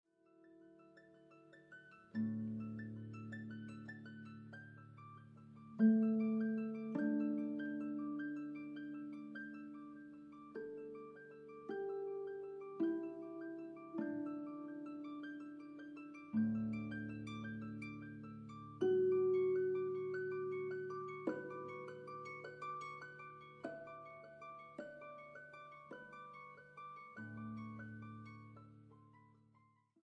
Boonkker Audio Tacubaya, Ciudad de México.